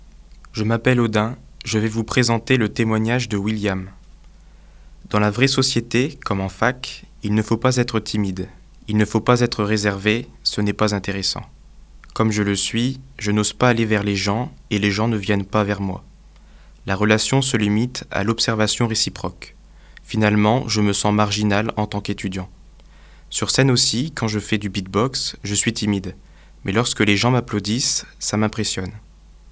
étudiants français et chinois de l'Université Lille 1 qui ont prêté leur voix.